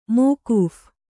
♪ mōkūph